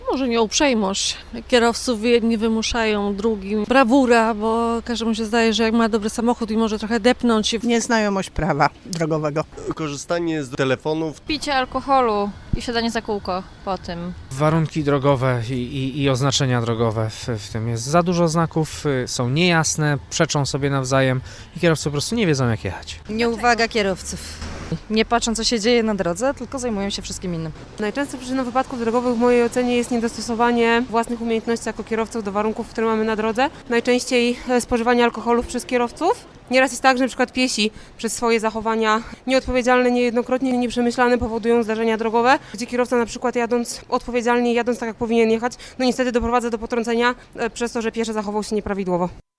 O niewłaściwe zachowania za kółkiem pytamy też w sondzie. – Brawura, korzystanie z telefonu podczas jazdy, alkohol – mówią mieszkańcy.
kierowcy_sonda.mp3